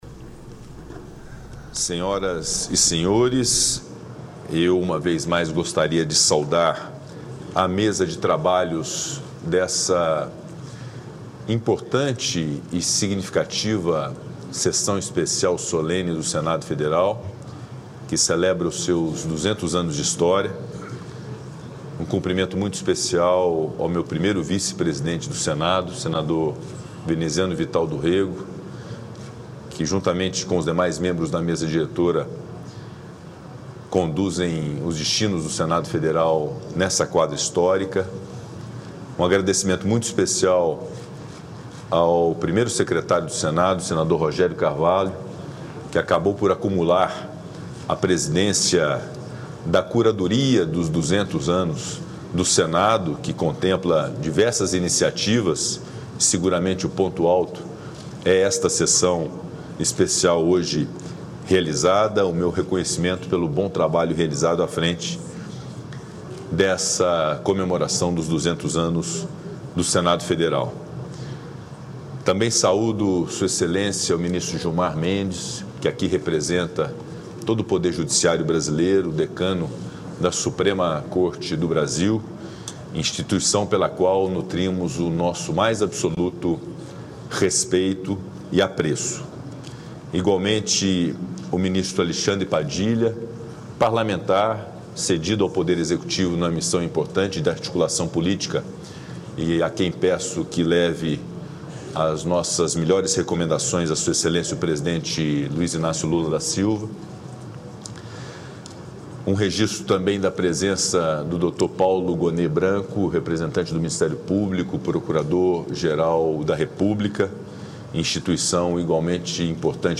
Pronunciamento do presidente do Senado, Rodrigo Pacheco, durante a comemoração dos 200 anos do Senado